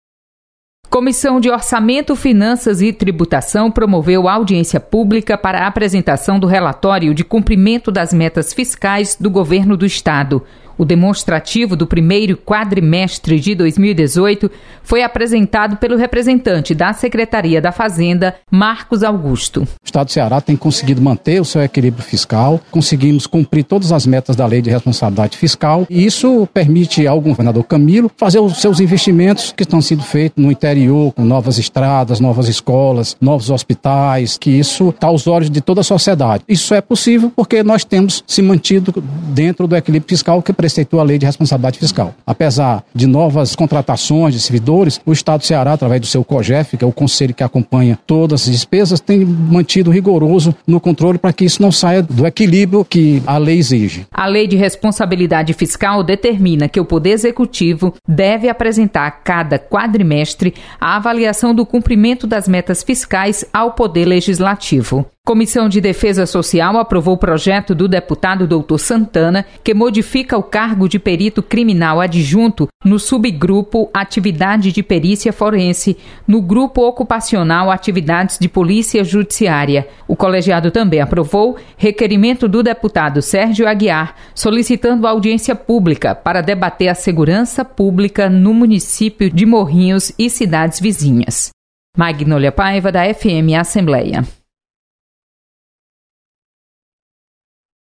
Acompanhe o resumo das comissões técnicas permanentes da Assembleia com a repórter